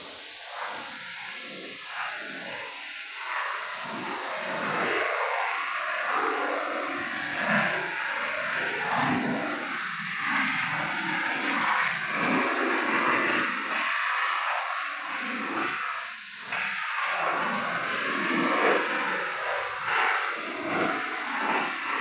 Is noise satanic? Practicing removal on that unlistenable boston horowitz pirate. This the removed part.